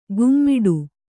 ♪ gummiḍu